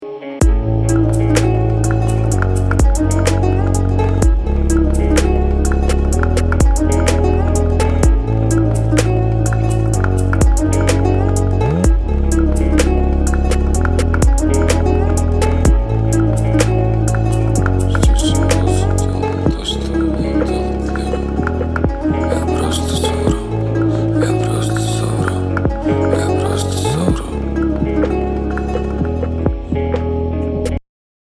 • Качество: 256, Stereo
гитара
восточные мотивы
атмосферные
спокойные
струнные
инструментальные
Chill Trap